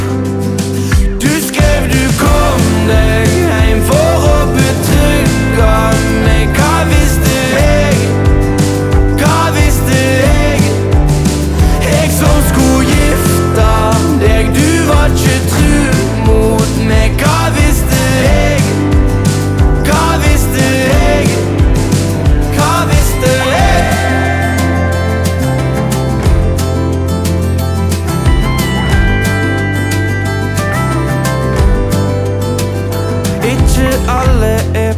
2025-05-09 Жанр: Кантри Длительность